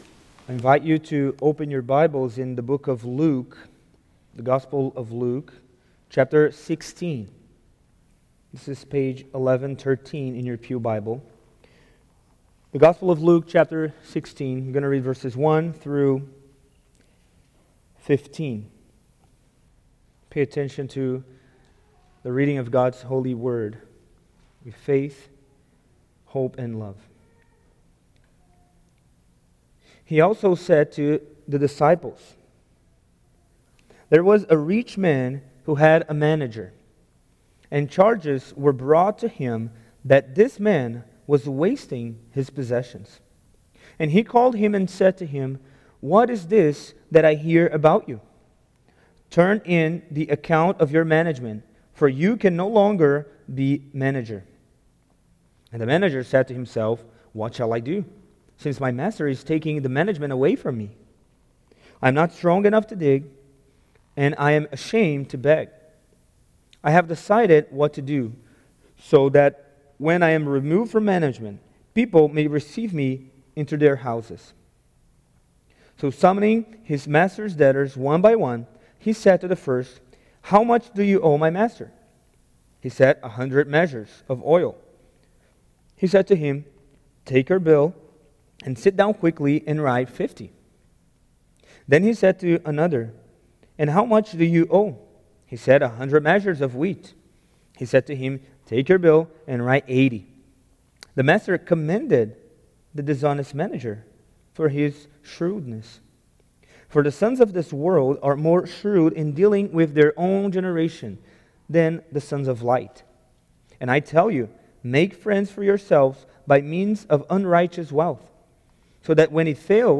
Treasure Series Various Sermons Book Luke Watch Listen Save In Luke 16:1-15, Jesus emphasizes seeking the kingdom of God instead of worldly wealth.